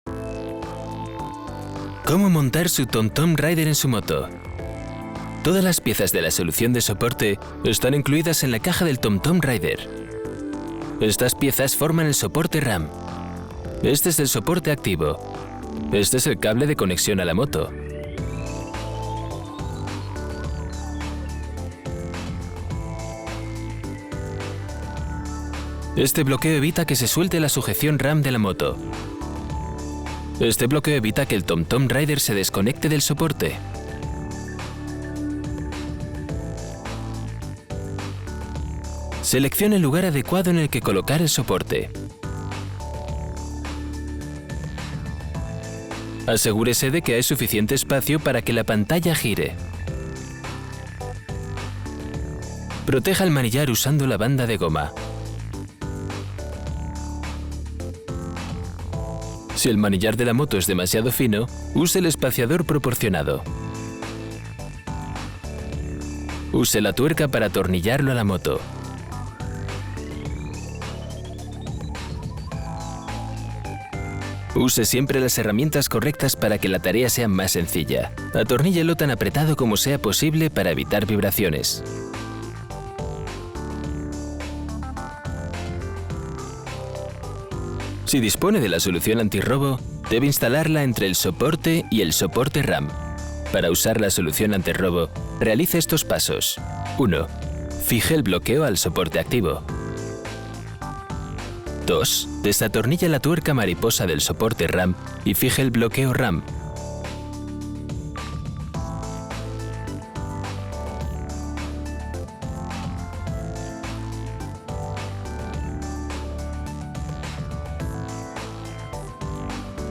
sehr variabel
Jung (18-30)
Industriefilm, ruhig, sanft, luxuriös
Commercial (Werbung)